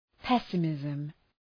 Προφορά
{‘pesə,mızm}